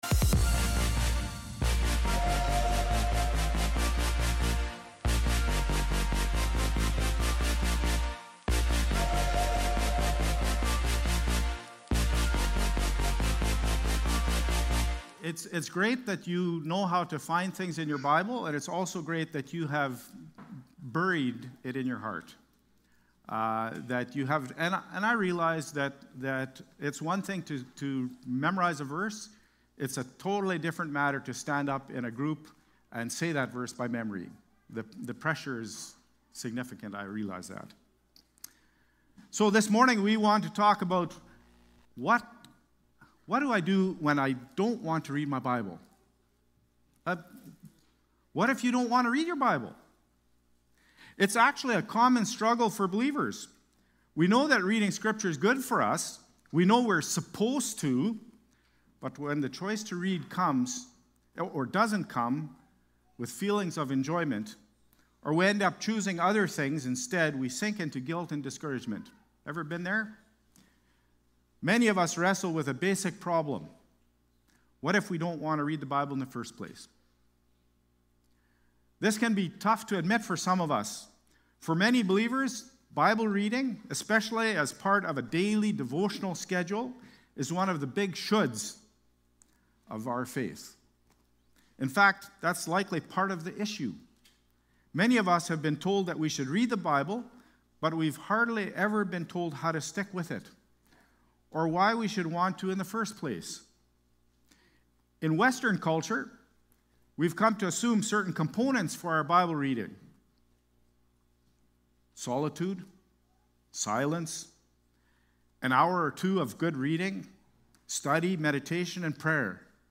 May-18-Worship-Service.mp3